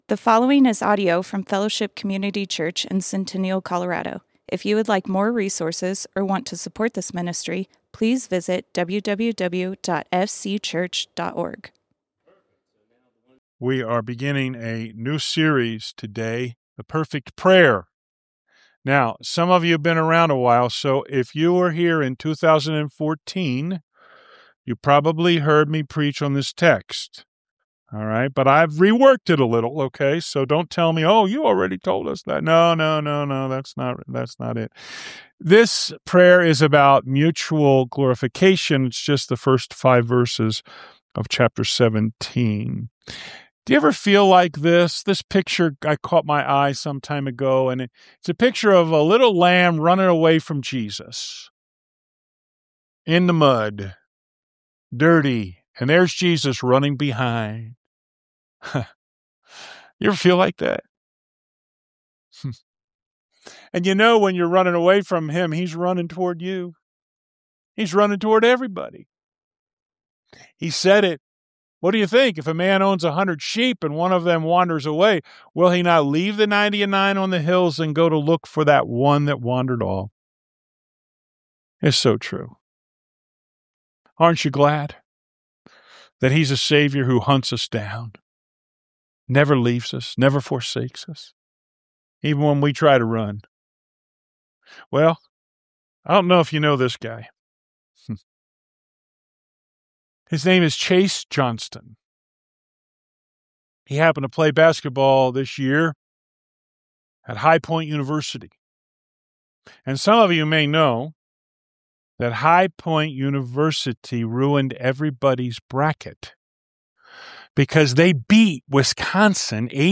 Fellowship Community Church - Sermons Mutual Glorification Play Episode Pause Episode Mute/Unmute Episode Rewind 10 Seconds 1x Fast Forward 30 seconds 00:00 / 33:23 Subscribe Share RSS Feed Share Link Embed